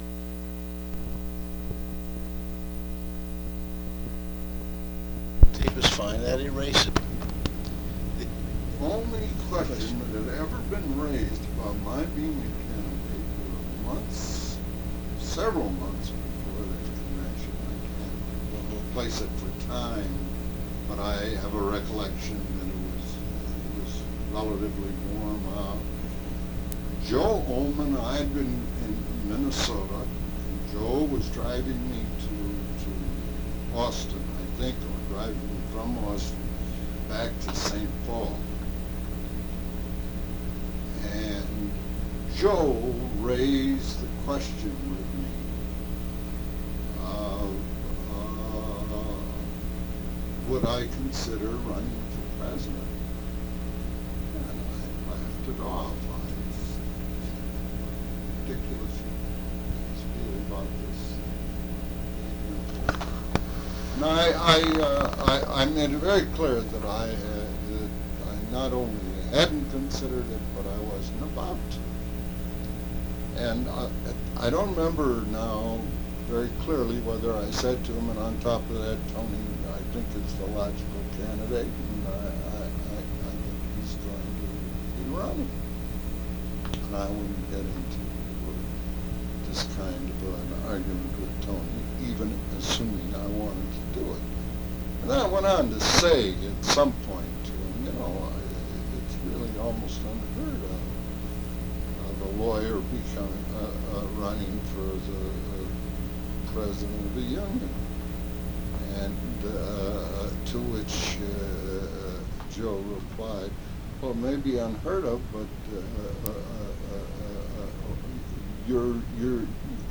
Oral History Interview Side A.mp3